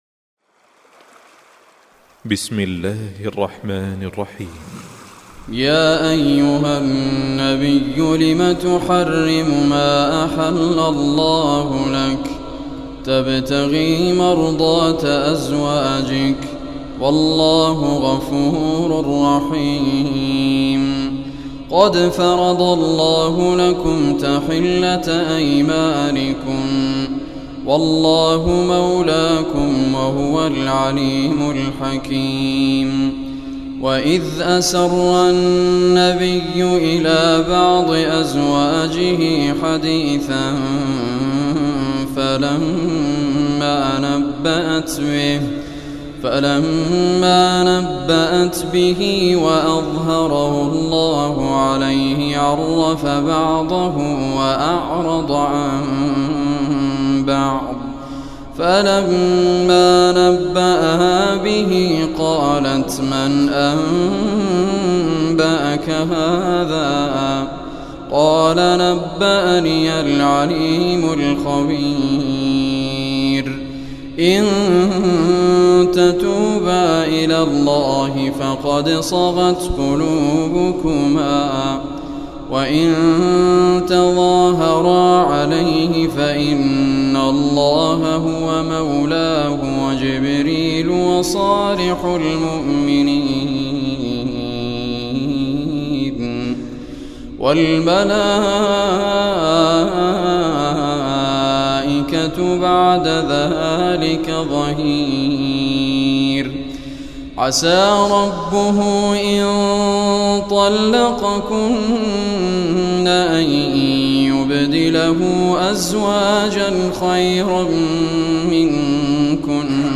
Surah Tahrim MP3 Recitation by Sheikh Raad Kurdi
Surah Tahrim, listen or play online mp3 tilawat / recitation in arabic in the beautiful voice of Sheikh Muhammad Raad al Kurdi.